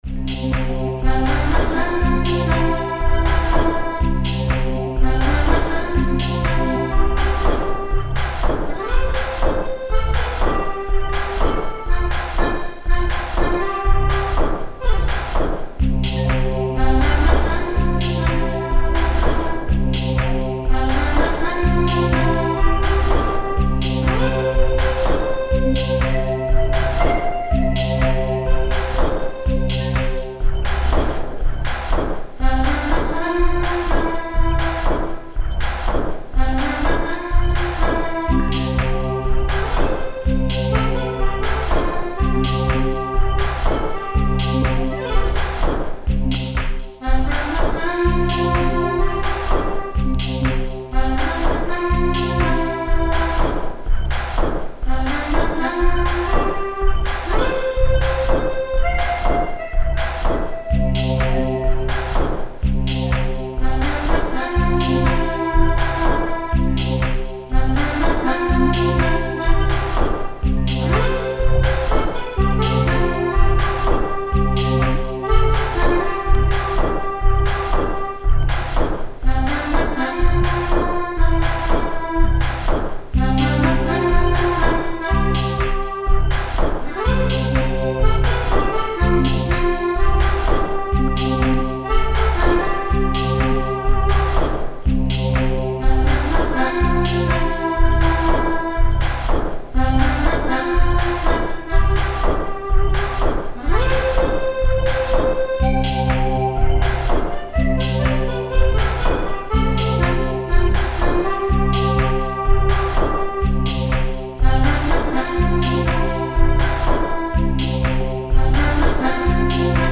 Etno /Creative Commons License 4.0 / noncommercial use free